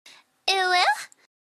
uwu sound yas
uwu-sound-yas.mp3